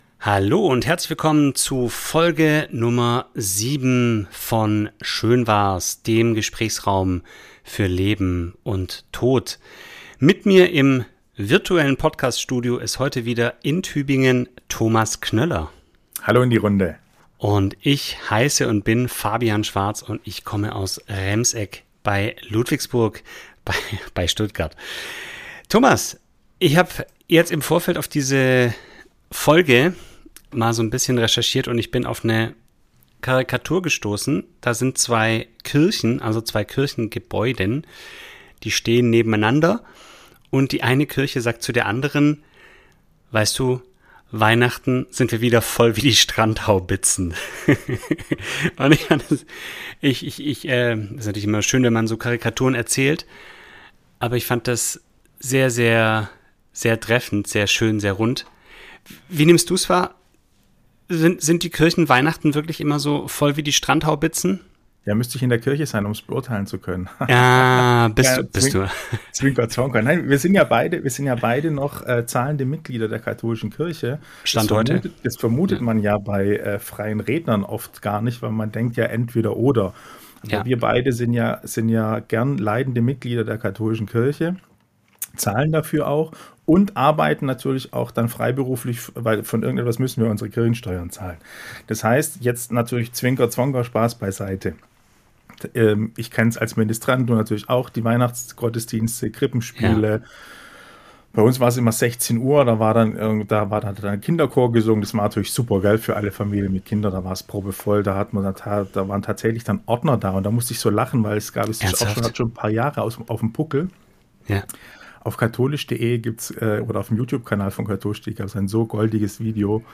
In dieser Folge reden die beiden Trauerredner